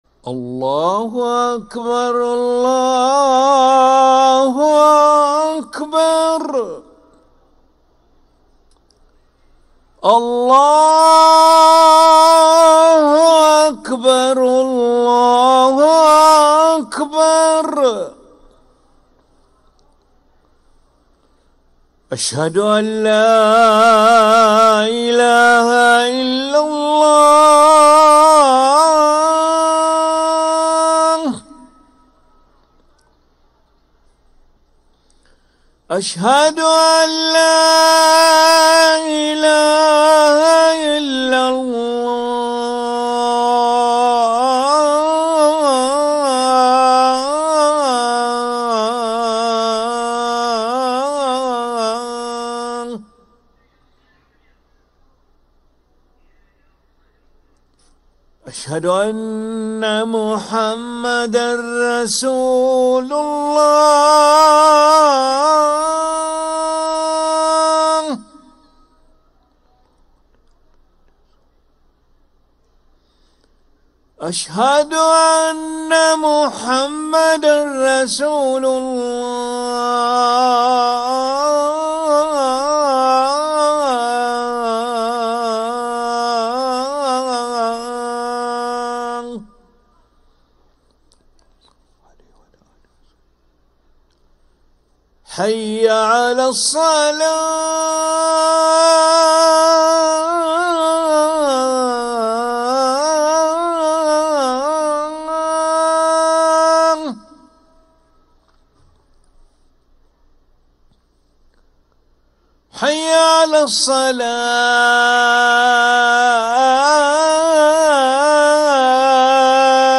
أذان العشاء للمؤذن علي ملا الأحد 19 رجب 1446هـ > ١٤٤٦ 🕋 > ركن الأذان 🕋 > المزيد - تلاوات الحرمين